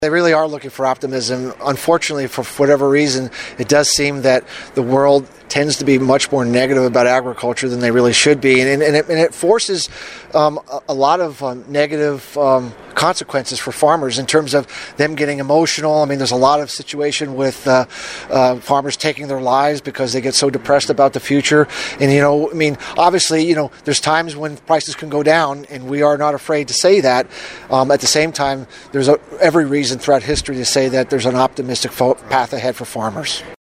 The 20th annual Kentucky Soybean Promotion Day at Murray State University Tuesday provided a reality check, a word of optimism for the future of agriculture, and a look at potential weather influences.